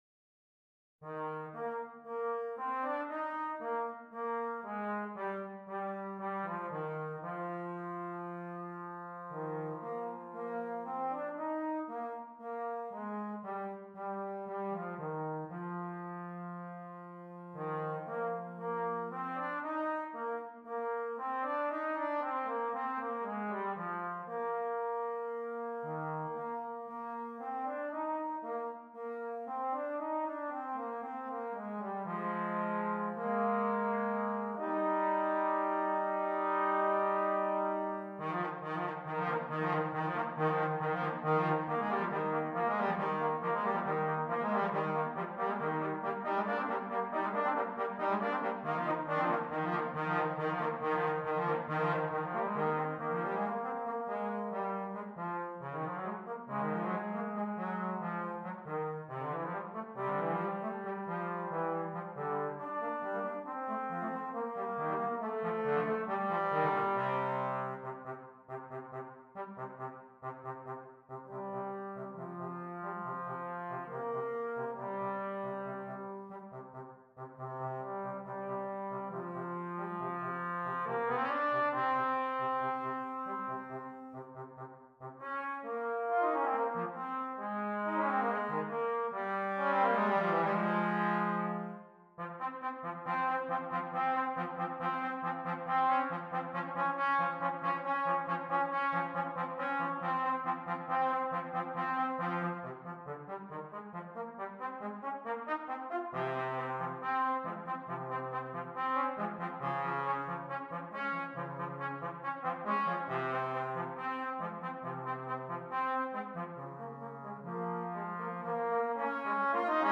2 Trombones